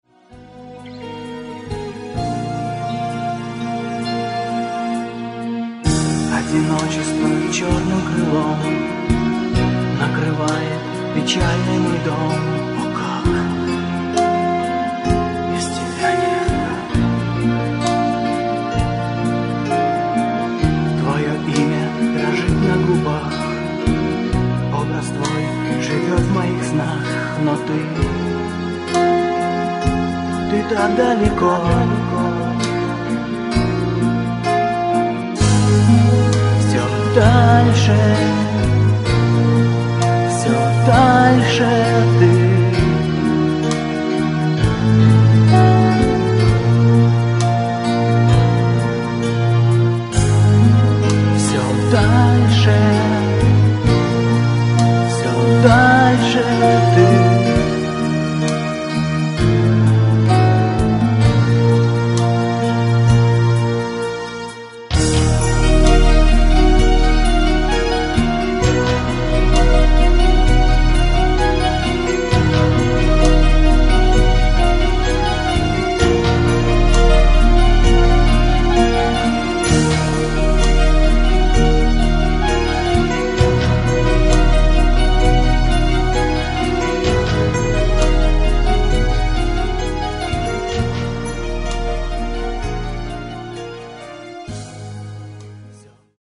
Клавиши, гитара, вокал, перкуссия
фрагмент (564 k) - mono, 48 kbps, 44 kHz